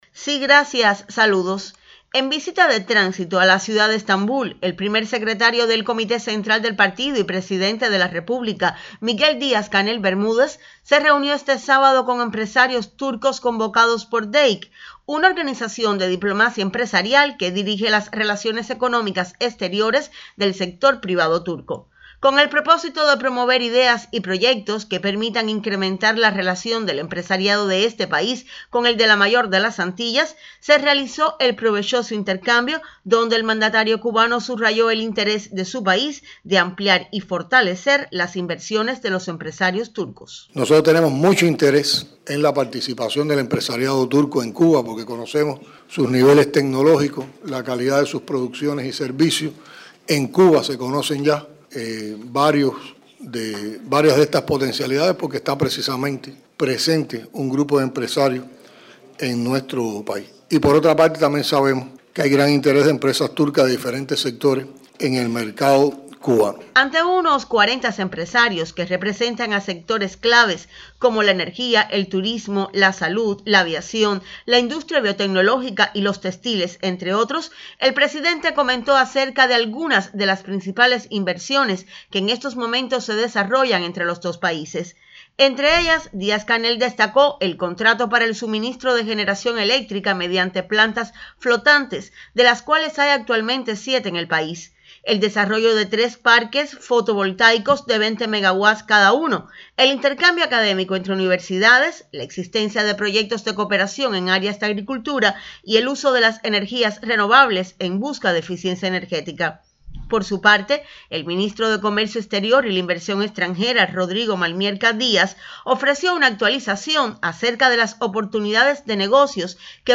El intercambio que tuvo lugar este sábado en Estambul entre la delegación de alto de nivel de Cuba, que encabeza el presidente Díaz-Canel Bermúdez, y un grupo de empresarios turcos que tienen o quieren tener la vivencia de emprender proyectos en la Isla, es señal de que, como dijo allí un representante del país euroasiático, existe el interés, en un camino que es de doble vía, de compartir experiencias en la revolución económica de Cuba.
dc_con_empresarios_turcos.mp3